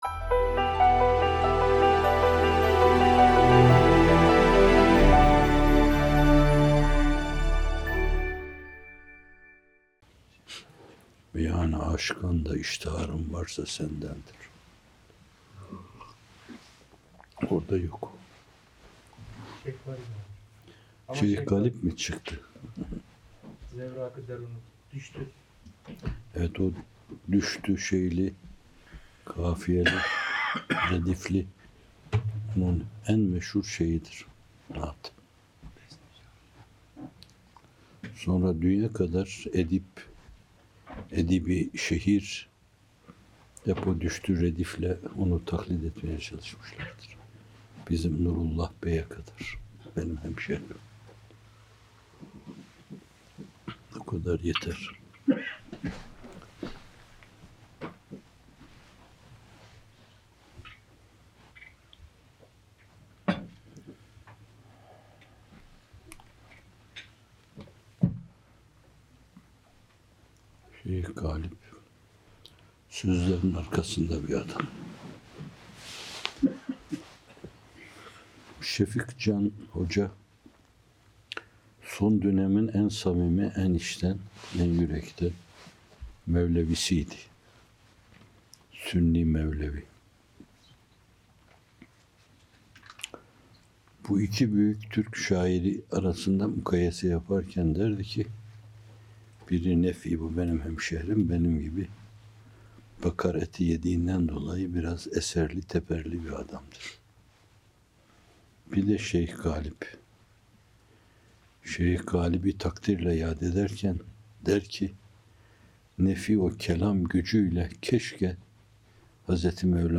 M. Fethullah Gülen Hocaefendinin 11 Kasım 2011 tarihinde yapmış olduğu ilk kez yayınlanan sohbeti. 00:23 Şeyh Galip…